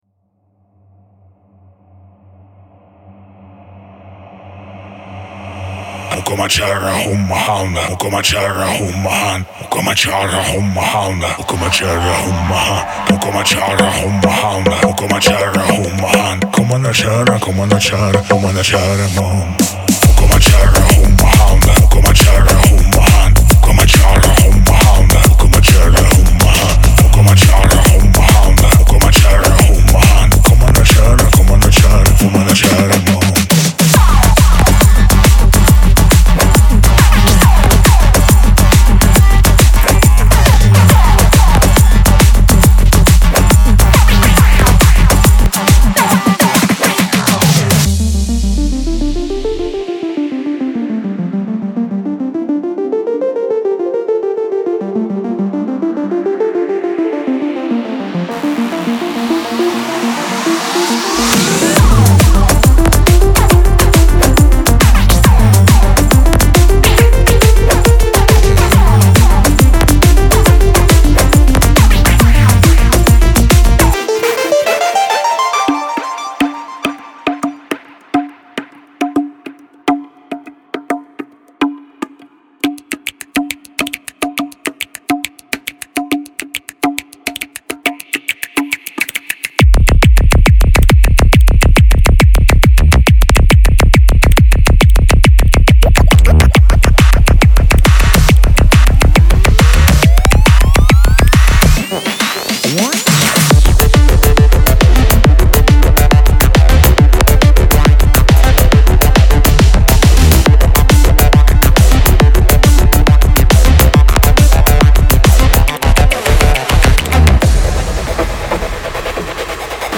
所有鼓点，合成器和循环的音色都经过精心处理，使其尽可能有力且温暖。
查看此病态的演示曲目，立即获取您的“精神精神”副本。
•160首单拍（包括现场录制的打击乐）
•45个Psy低音环
•44个萨满鼓循环